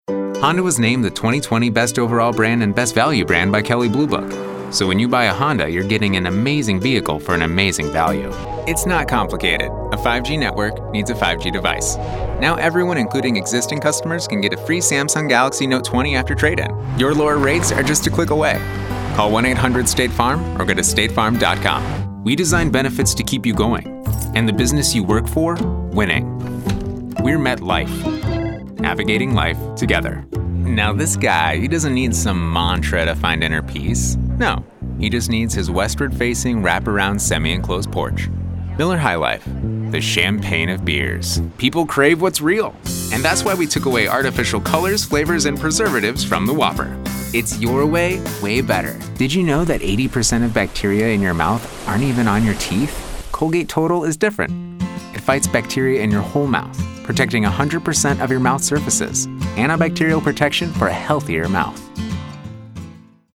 Commercial Voice Over with Heart